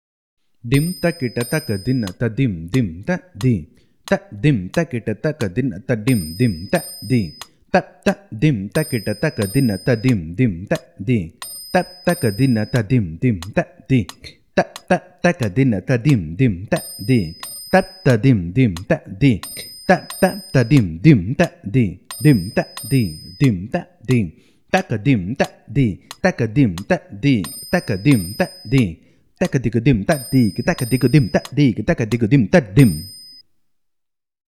This is a mukthayam of 48 beats, which is a combination of both chaturashra nade and trishra nade.
Konnakol